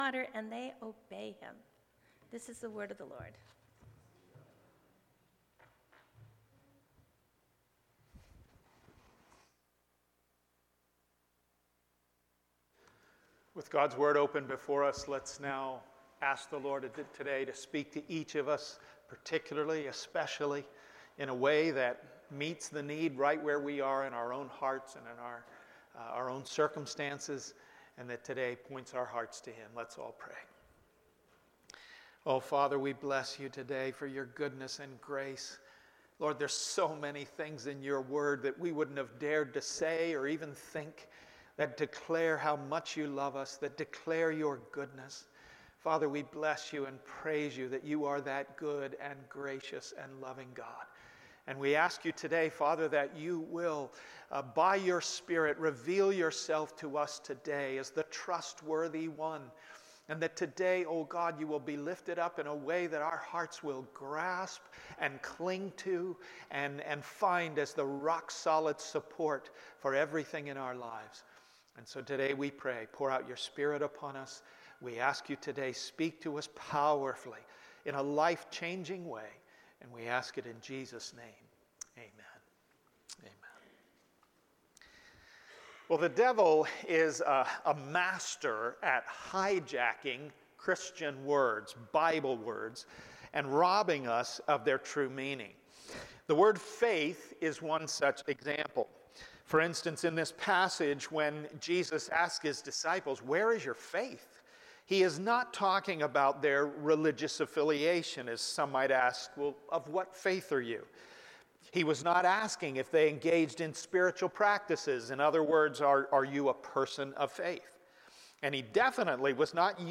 Passage: Luke 8:25 Sermon